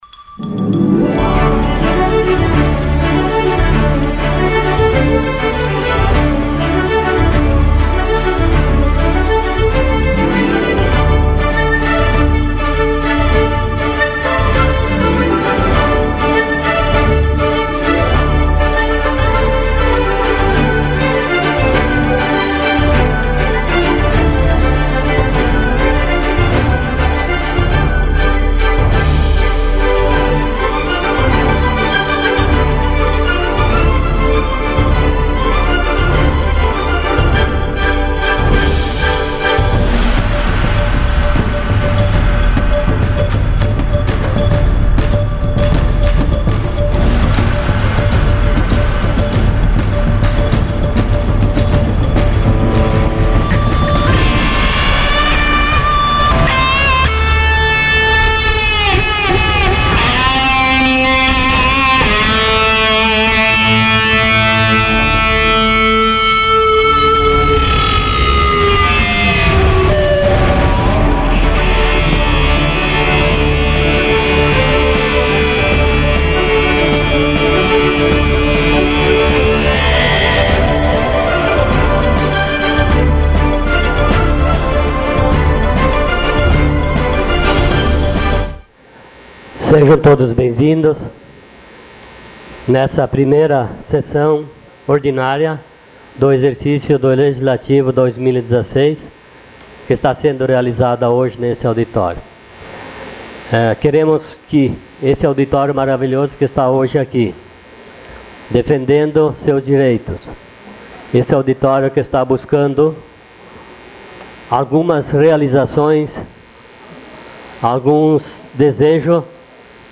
Sessão Ordinária dia 04 de fevereiro de 2016.